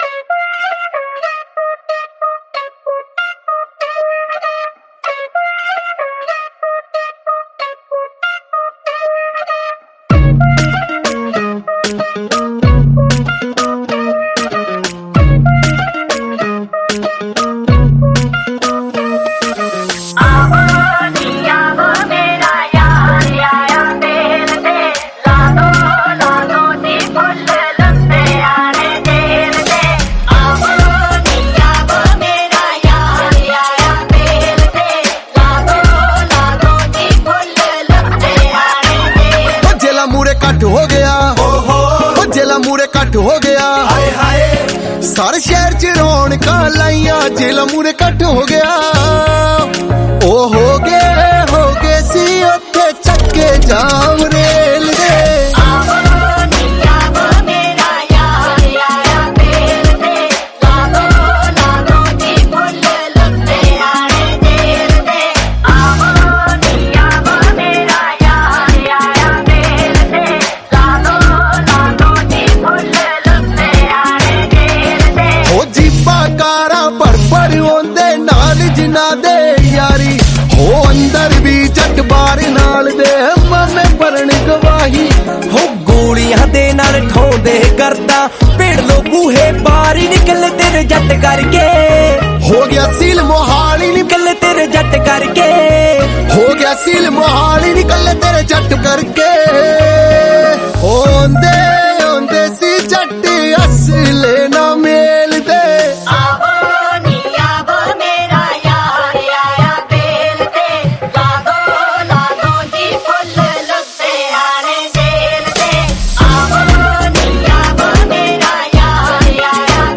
2. Punjabi Single Track